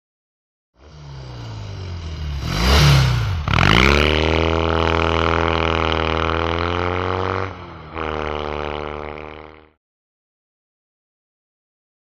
Motorcycle; By; Triumph Twin Up To Mic. Rev And Fast Away.